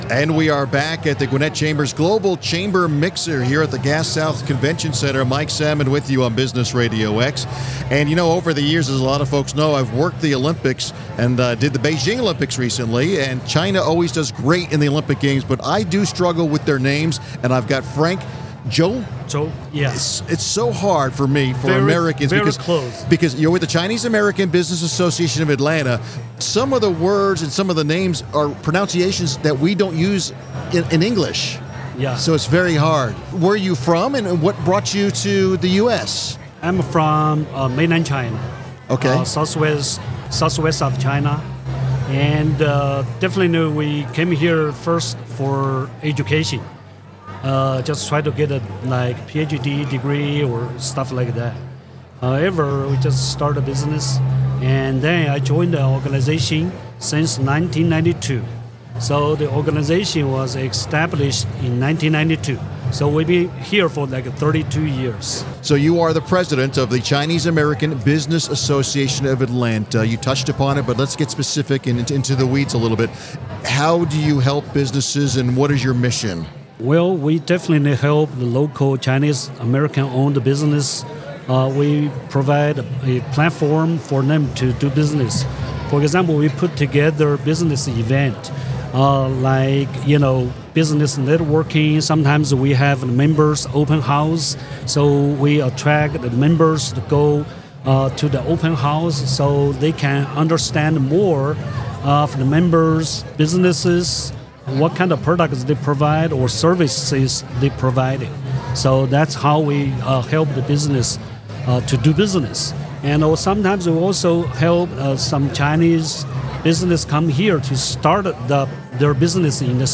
The Gwinnett Chamber of Commerce hosted it’s 2024 Global Chamber Mixer on November 7 at the Gas South Convention Center in Duluth, GA.